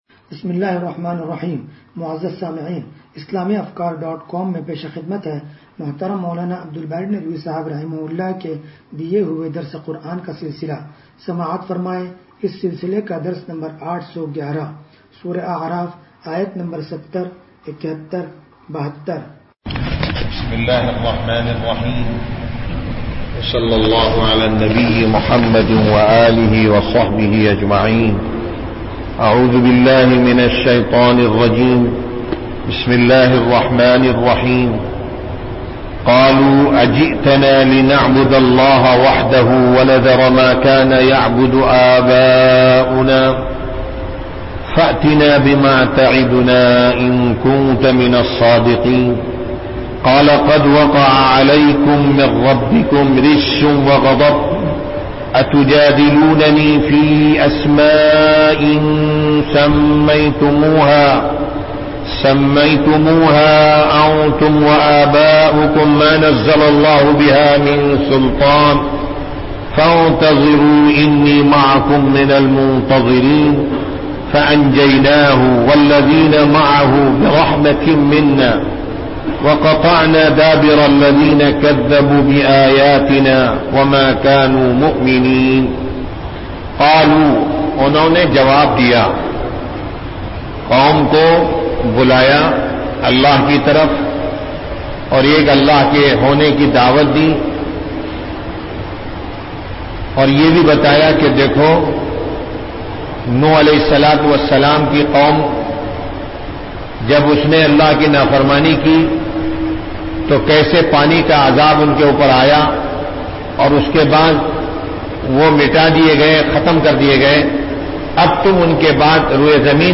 درس قرآن نمبر 0811